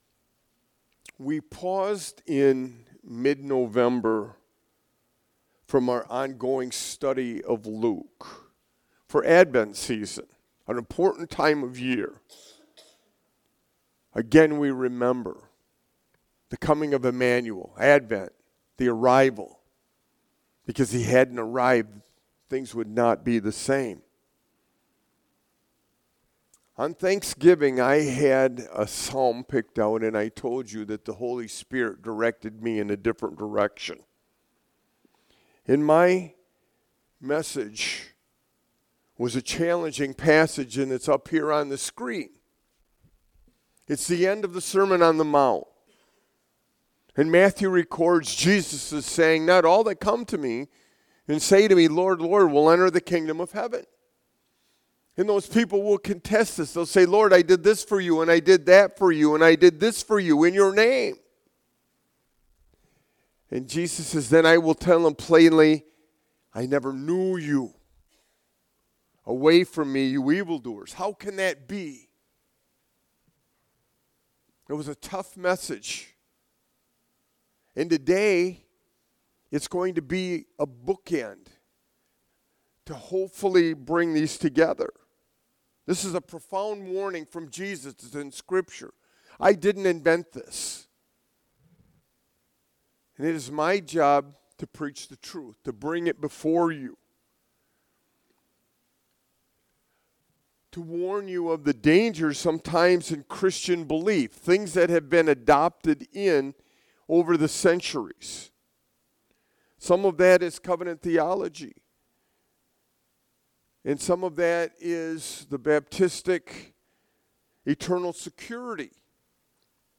Colossians 2:6-7; A bookend message to address the questions posed by our Thanksgiving sermon from Matthew 7.